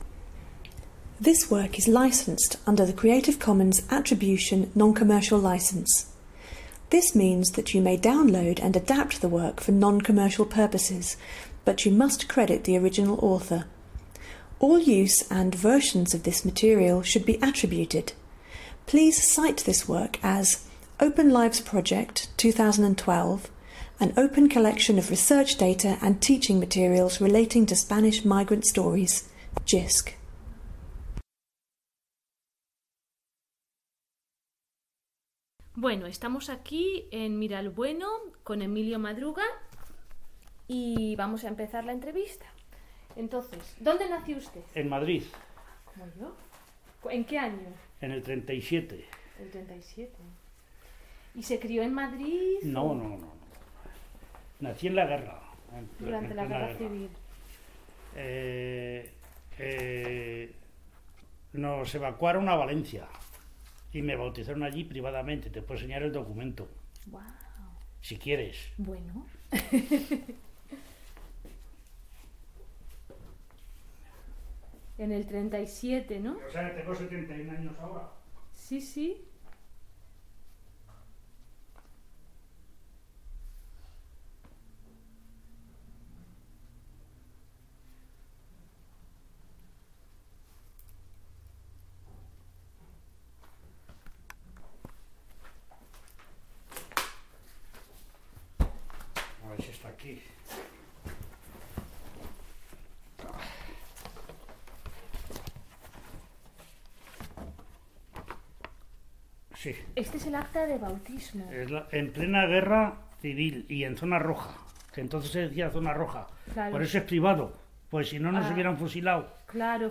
OpenLIVES Spanish emigre interviews